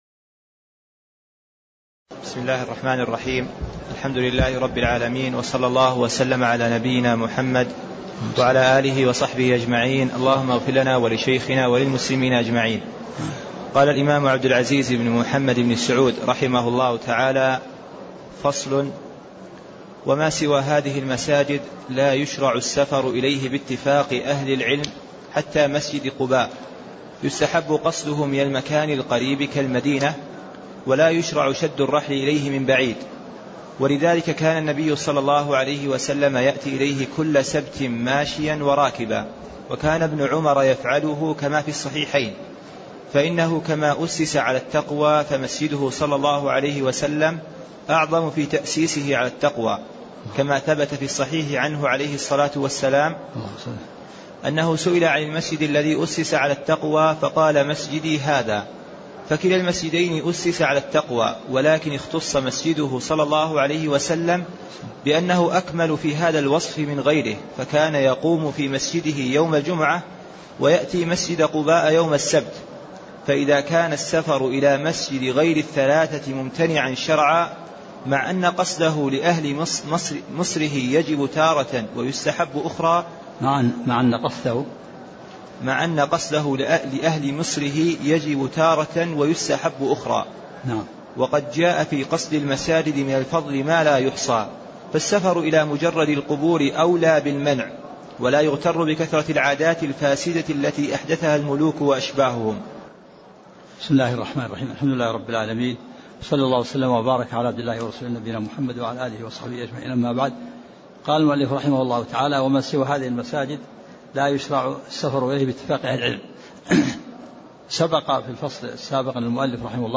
تاريخ النشر ٧ شعبان ١٤٣٤ هـ المكان: المسجد النبوي الشيخ